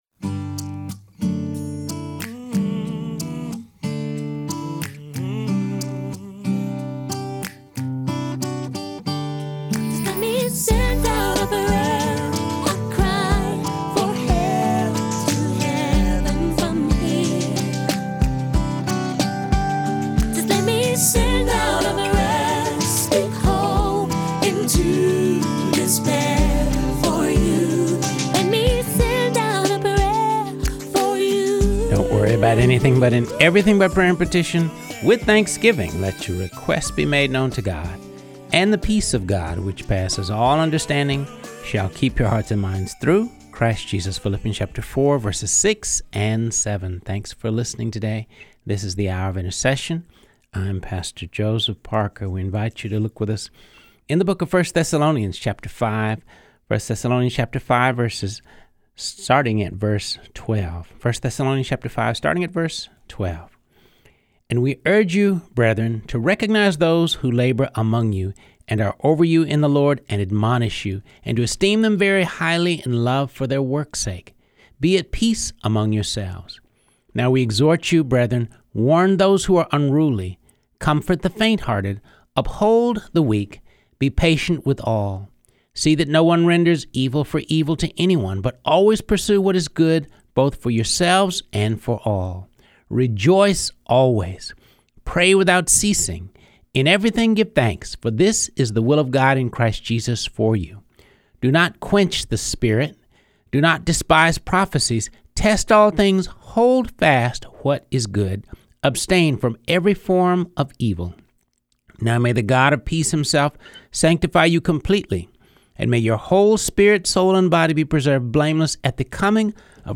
reading through the Bible.